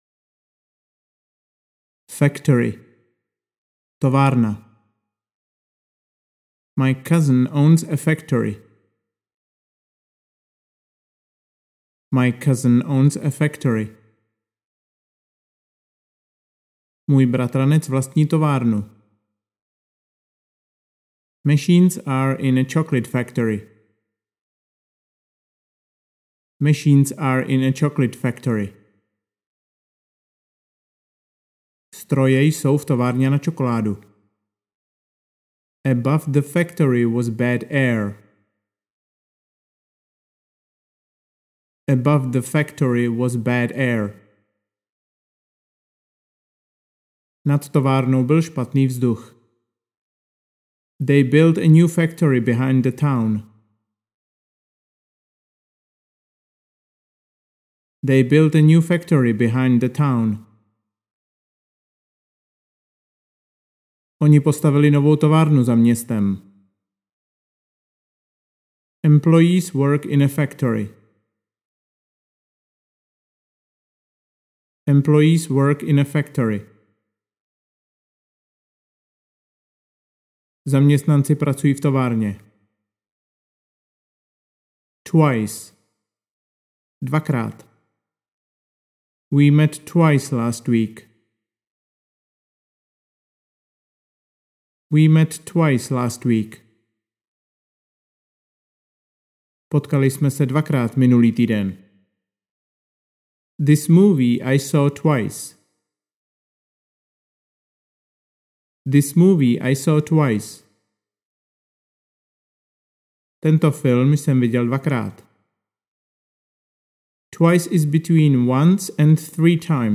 Anglická slovíčka - úroveň 4 pro pokročilé audiokniha
Ukázka z knihy